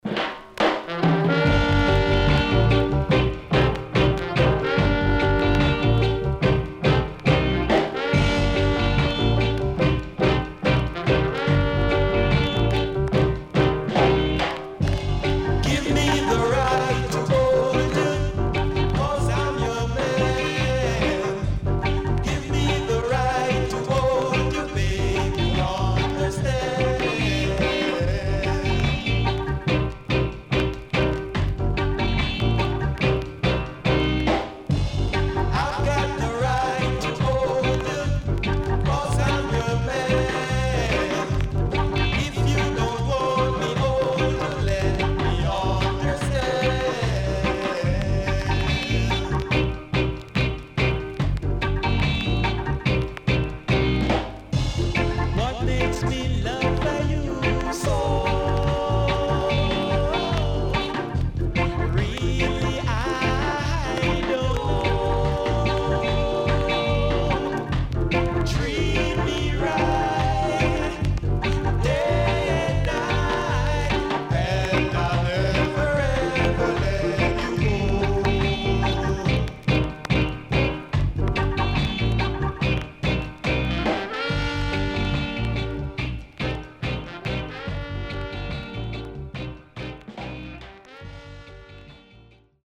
哀愁漂うEarly Reggaeの名曲の数々を収録した名盤
SIDE B:全体的にチリプチノイズ入ります。